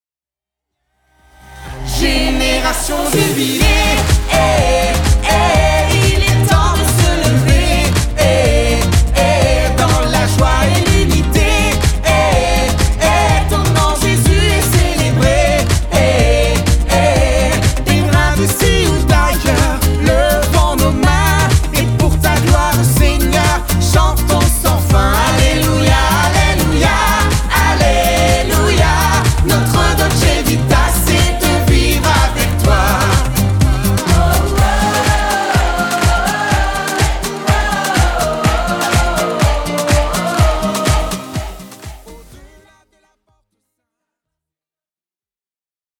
Avec son style festif, entre pop et musique estivale